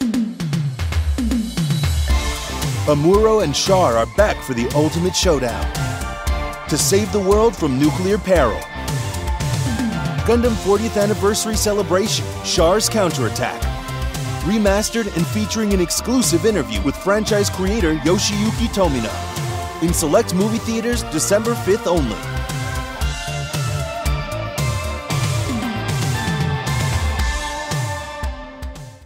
Teenager, Young Adult, Adult
Has Own Studio